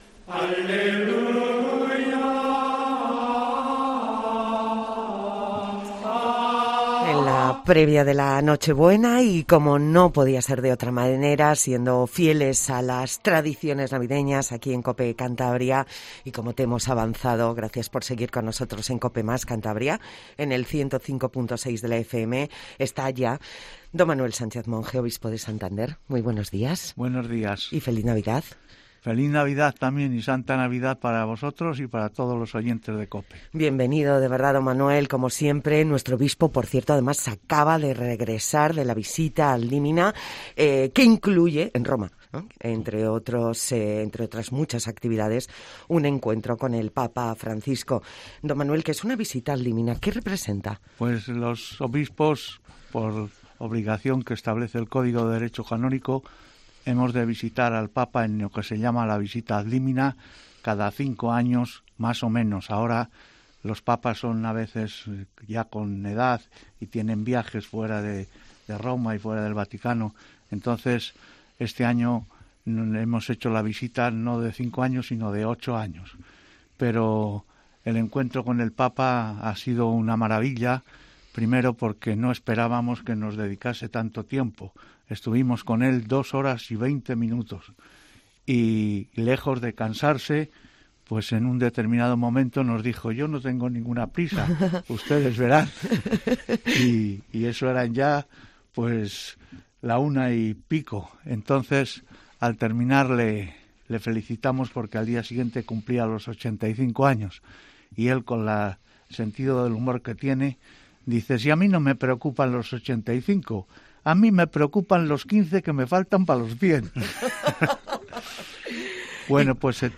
Don Manuel Sánchez Monge, en los estudios de Cope Cantabria antes de la llegada de la Navidad